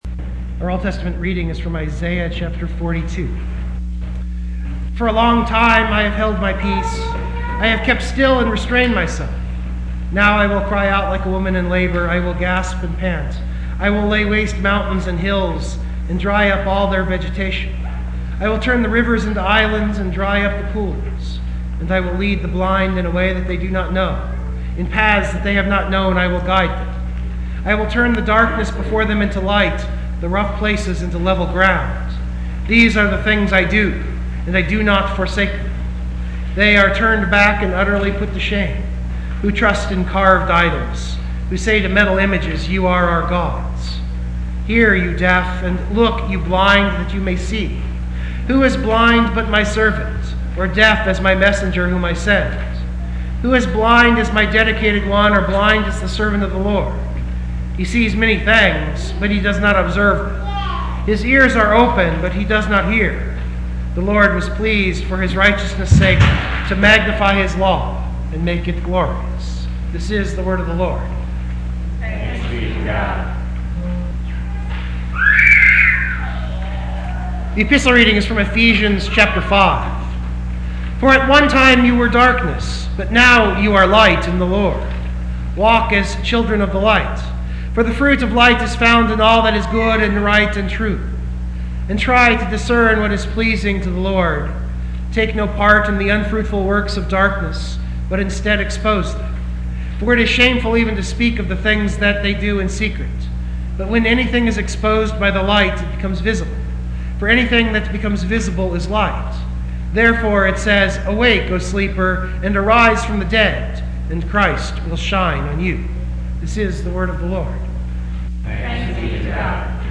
33014MBSermon.mp3